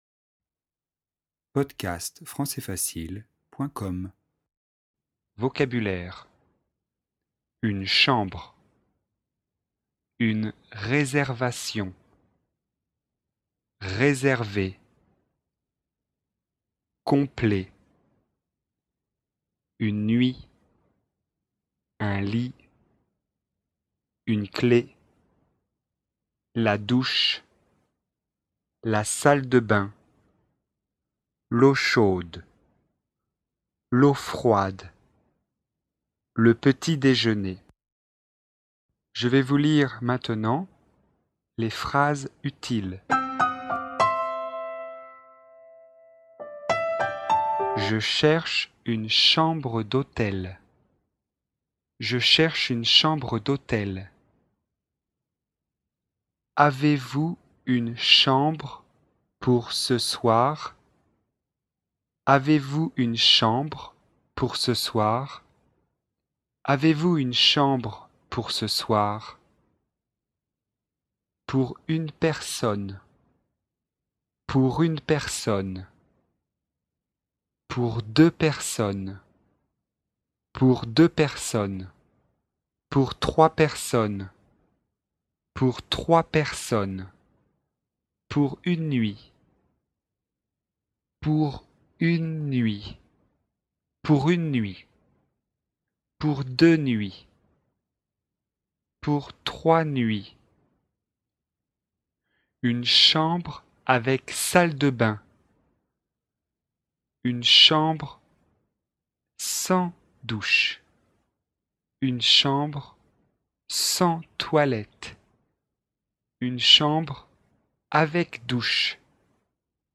Leçon de communication et de vocabulaire, niveau débutant (A2), sur le thème de l’hôtellerie.